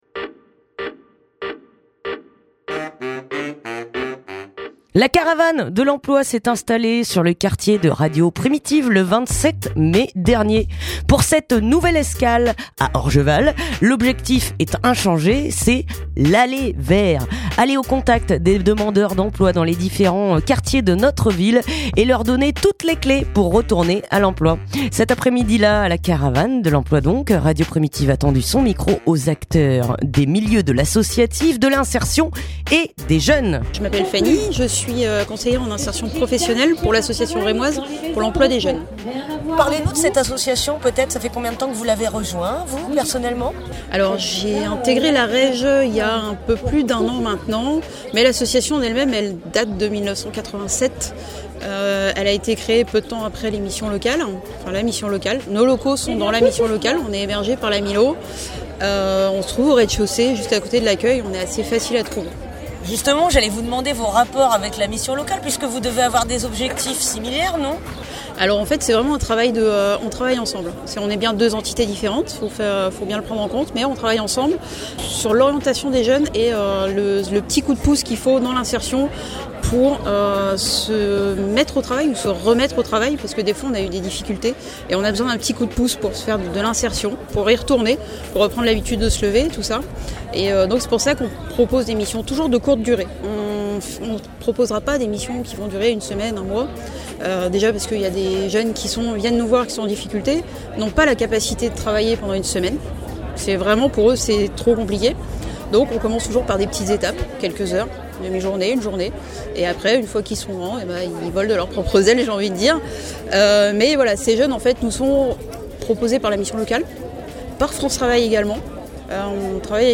Reportage place Simone de Beauvoir